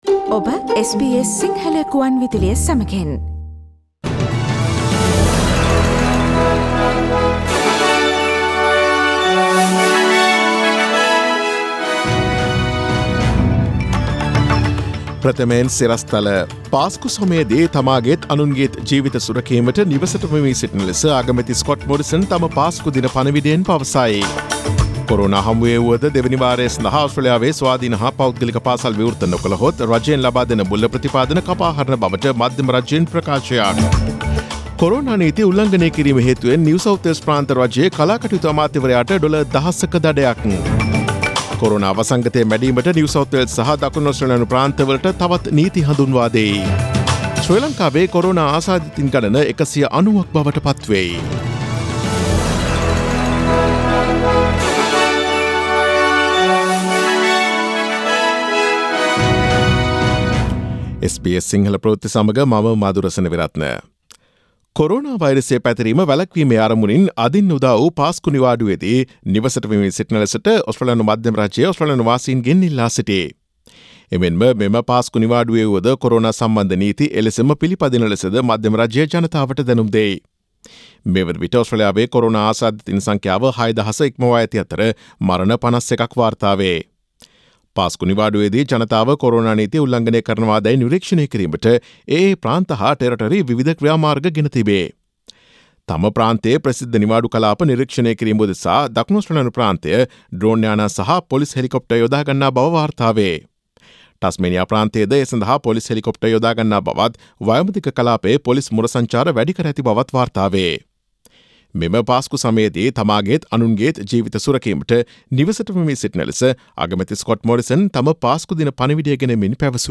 Daily News bulletin of SBS Sinhala Service: Friday 10 April 2020
Today’s news bulletin of SBS Sinhala Radio – Friday 10 April 2020 Listen to SBS Sinhala Radio on Monday, Tuesday, Thursday and Friday between 11 am to 12 noon